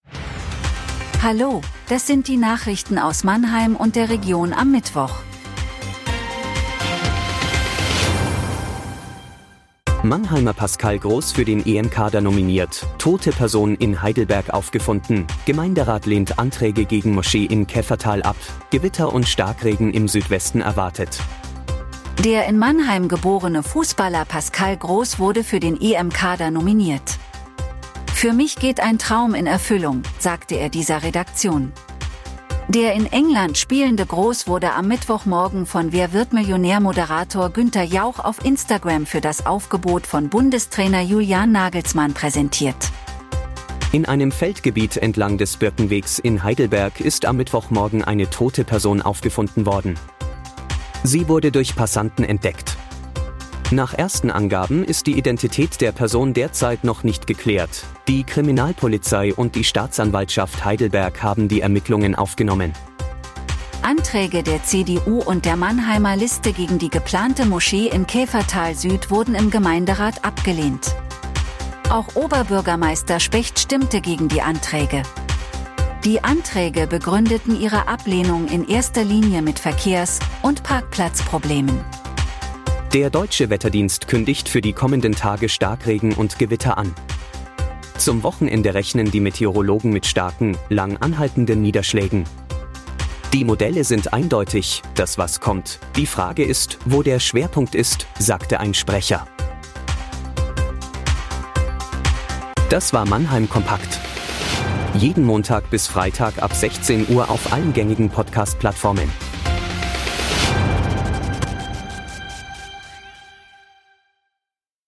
Der Nachrichten-Podcast des MANNHEIMER MORGEN